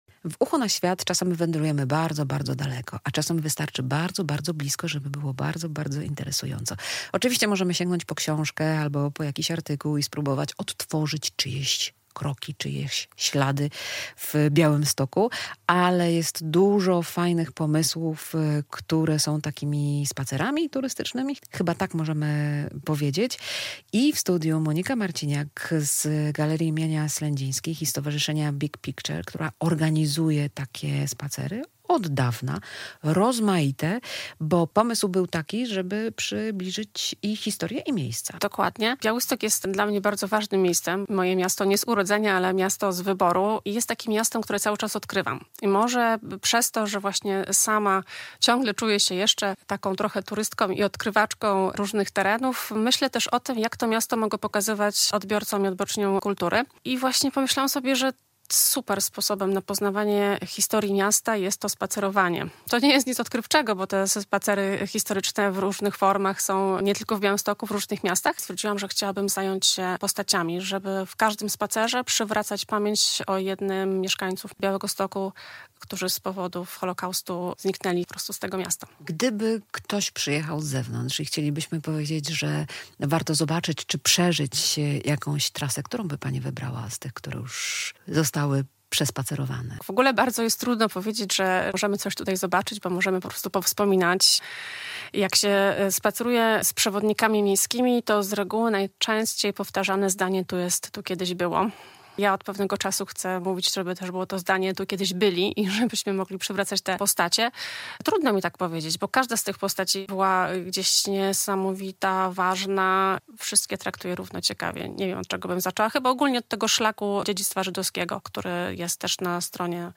Zapraszamy na rozmowę o najbardziej budżetowej i najciekawszej formie podróżowania - lokalnej ekspedycji. Zamiast dalekich kierunków, proponujemy bliskie horyzonty, które kryją niesamowite historie.